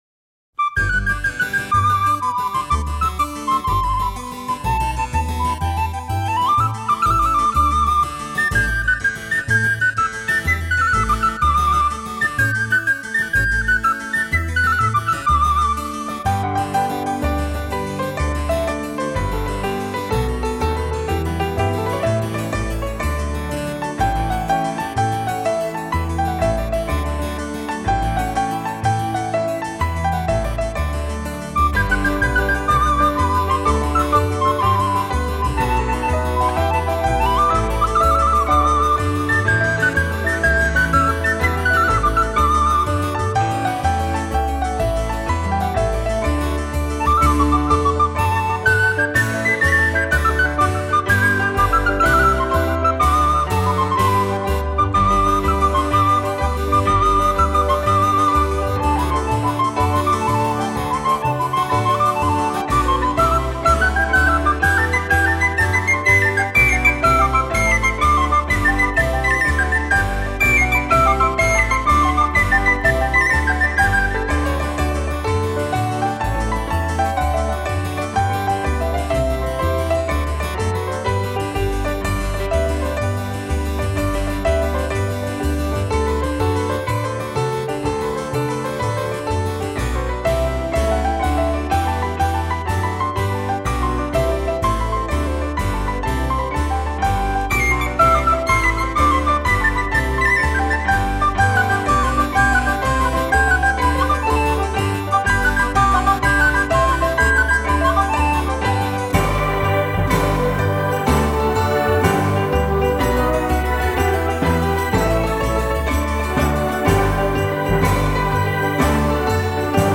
演绎一首首旋律优美动听、令人心旷神怡的乐曲